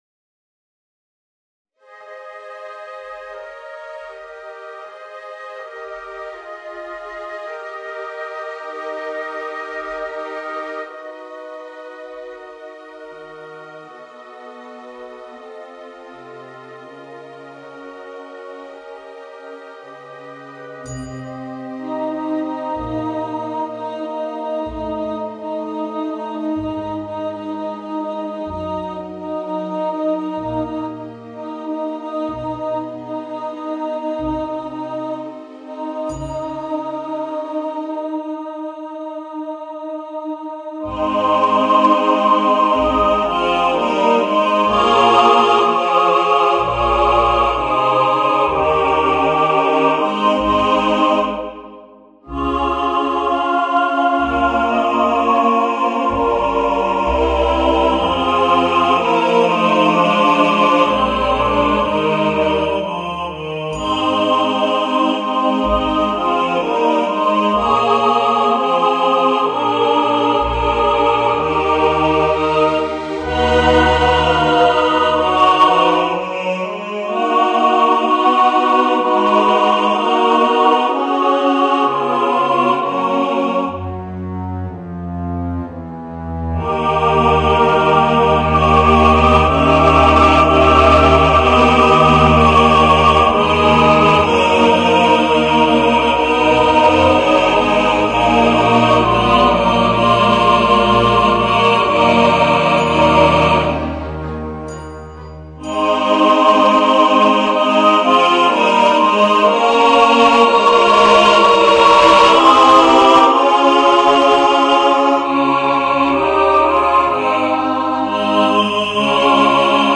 Voicing: Chorus and Orchestra